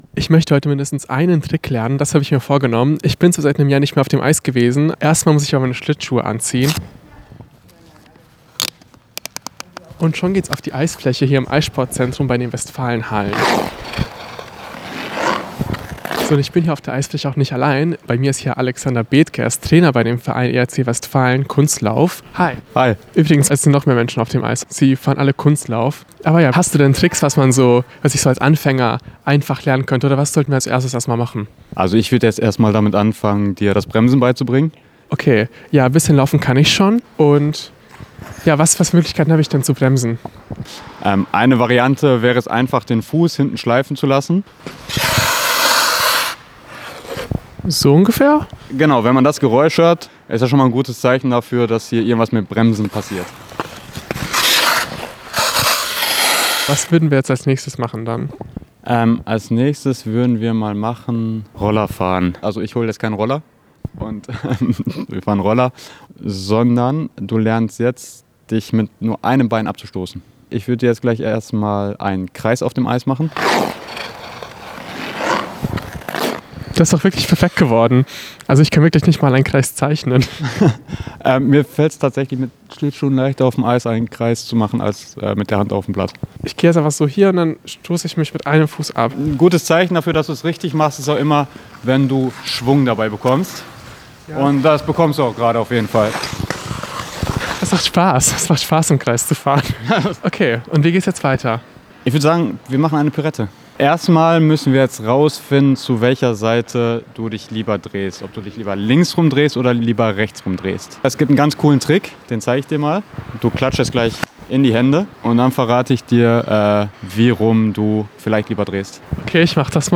Serie: Reportage